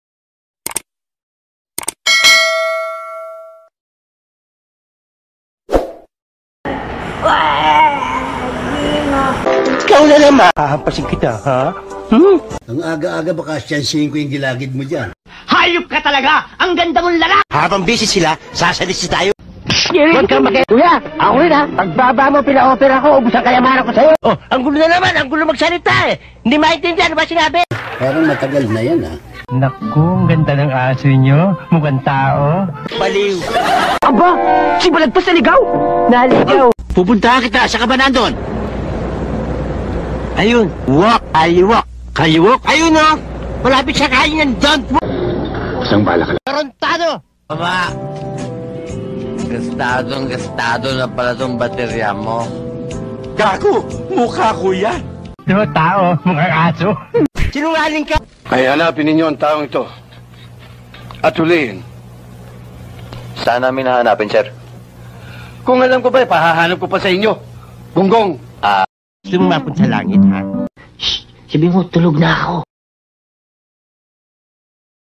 Funny Sound Effect For Video Sound Effects Free Download